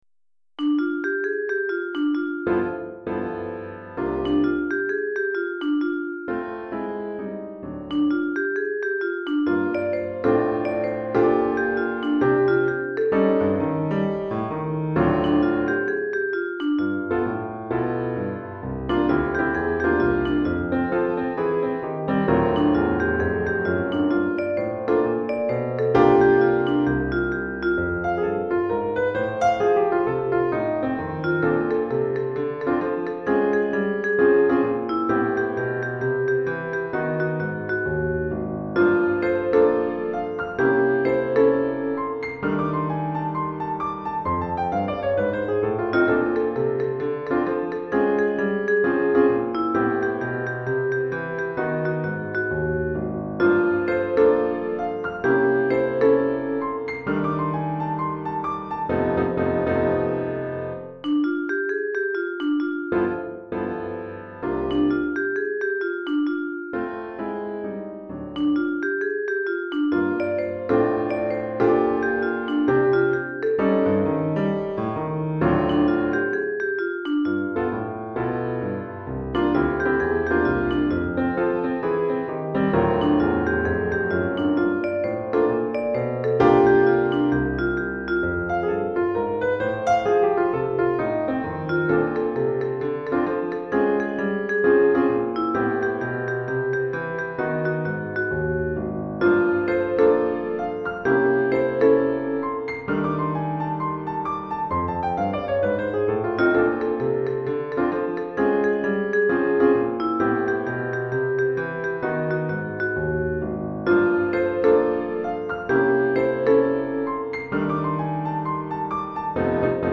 Chorale d'Enfants et Piano